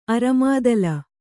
♪ aramādala